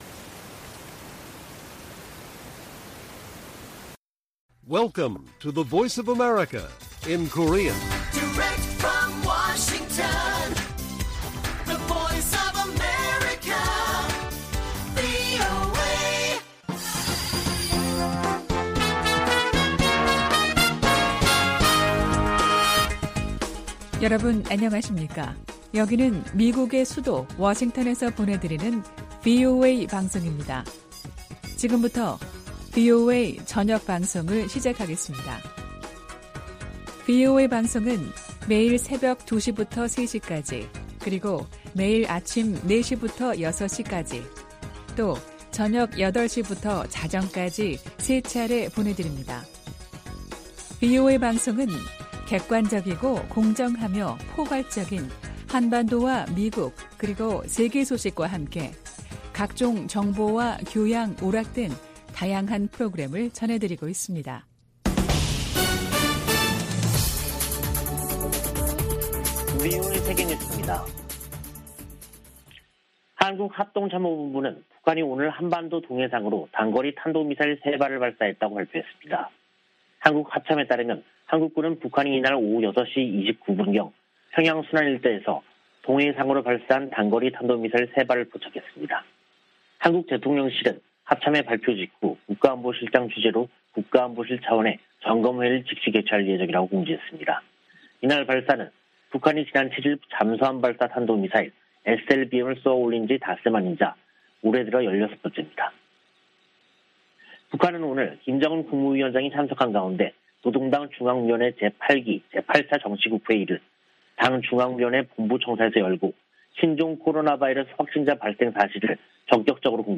VOA 한국어 간판 뉴스 프로그램 '뉴스 투데이', 2022년 5월 12일 1부 방송입니다. 북한이 또다시 탄도미사일을 발사했습니다.